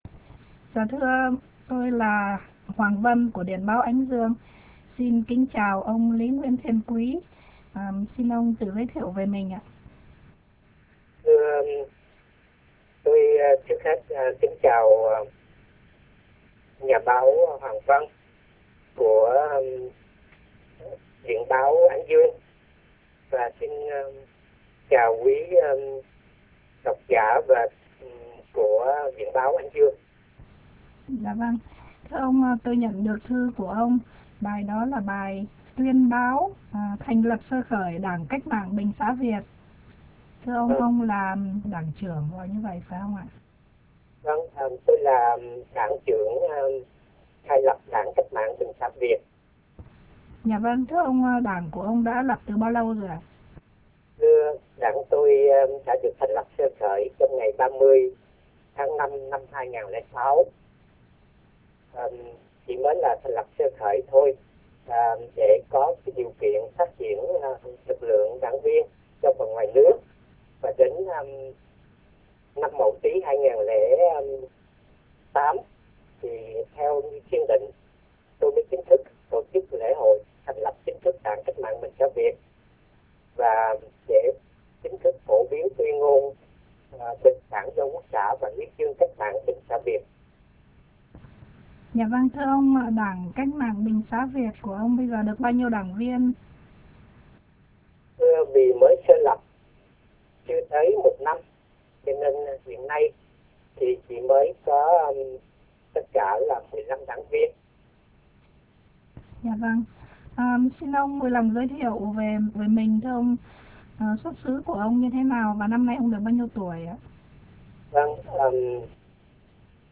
Phỏng vấn qua telephone